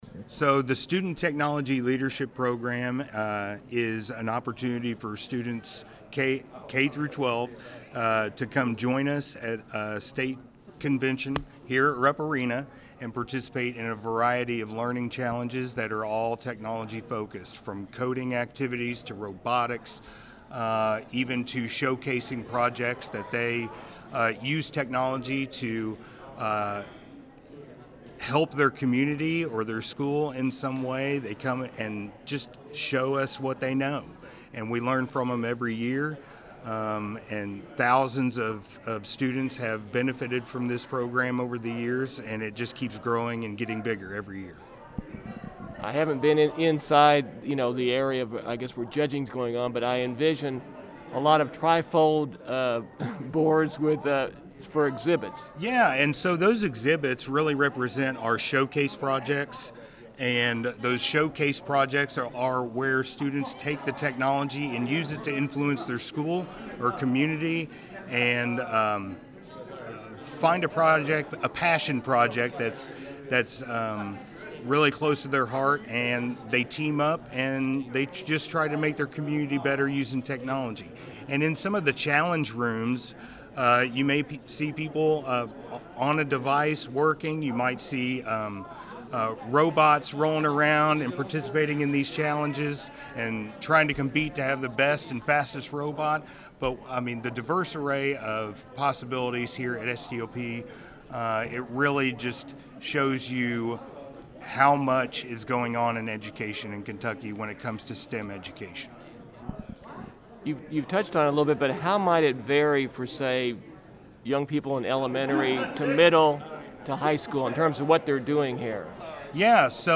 Today’s Interview segment